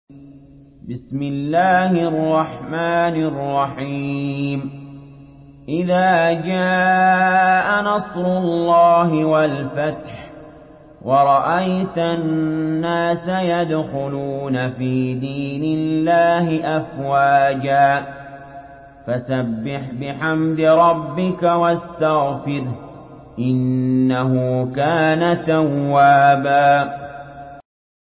Surah আন-নাসর MP3 by Ali Jaber in Hafs An Asim narration.
Murattal Hafs An Asim